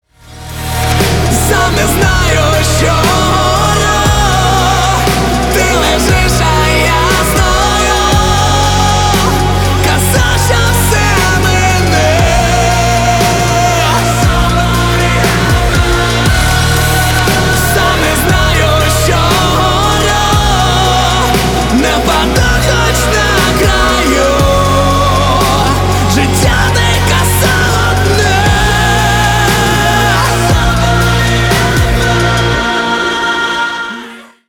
• Качество: 320, Stereo
мужской вокал
громкие
Alternative Metal